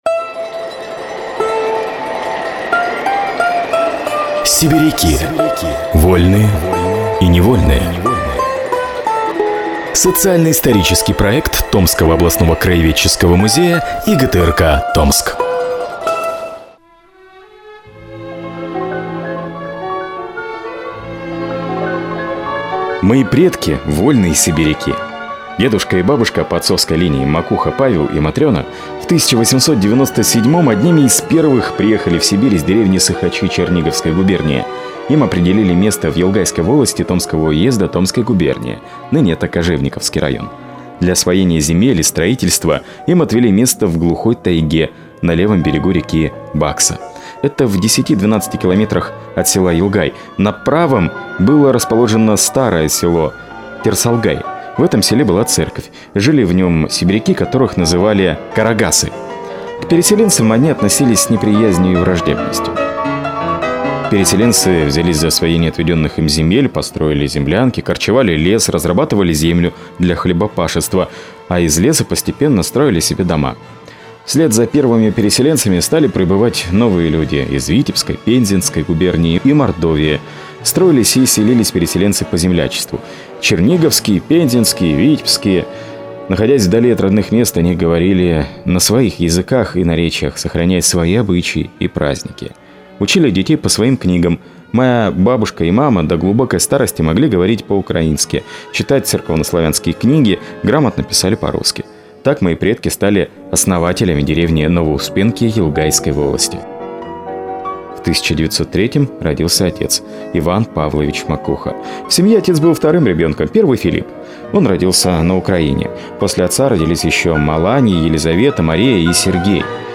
Мужской голос